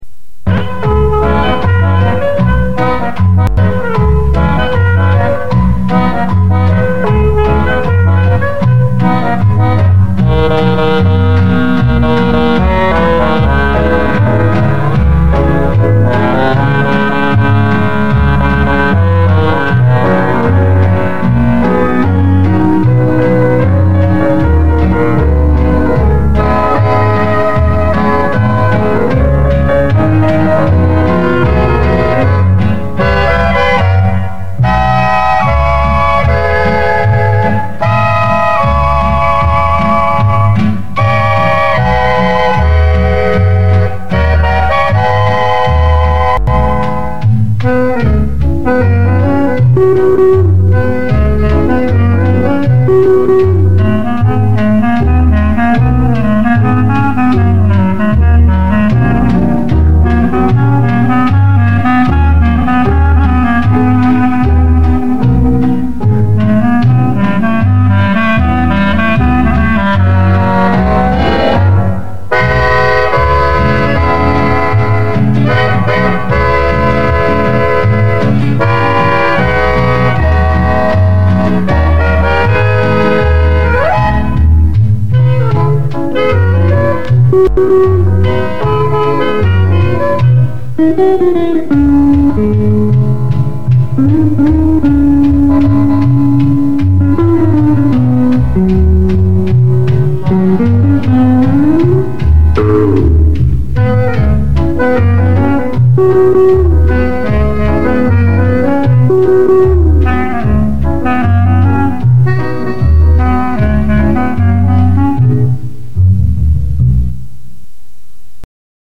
Очень прошу, угадайте пожалуйста несколько инструментальных мелодий.